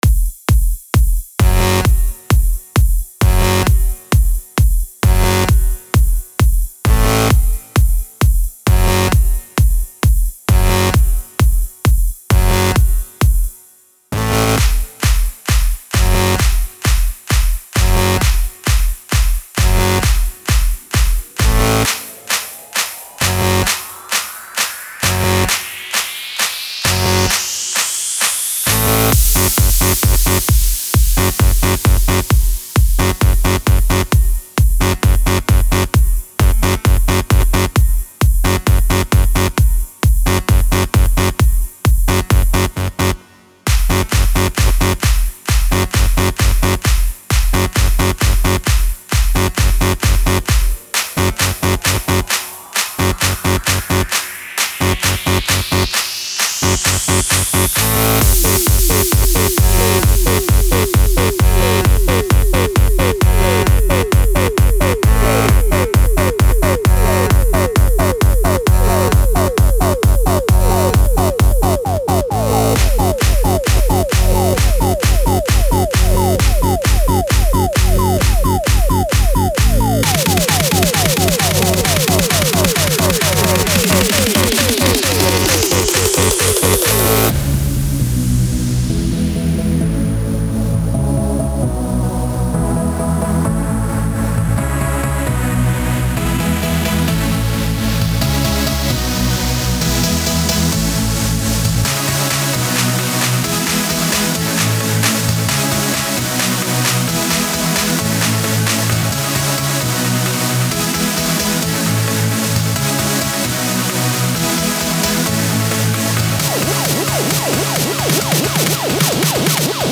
è soprattutto un brano da club